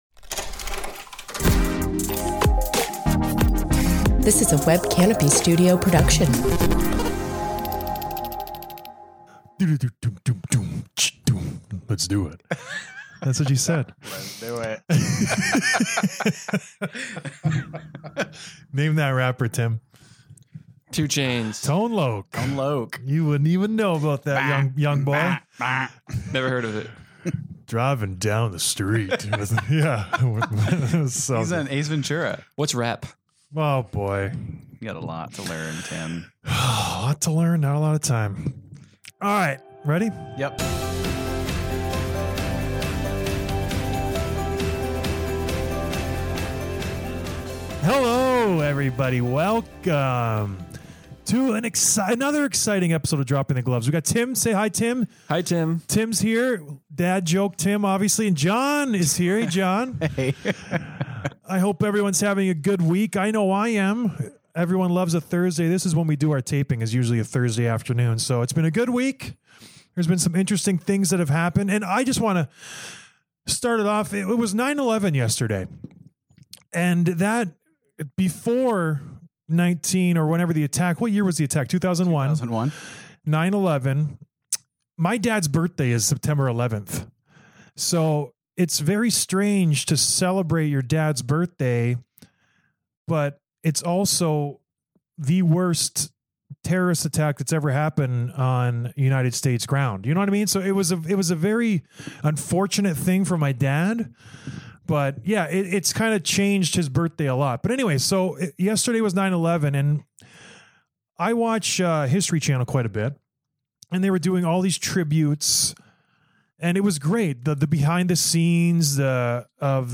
Interview with New Sharks Captain, Logan Couture
My good friend Logan Couture call's in fresh off of being named the San Jose Shark's newest captain!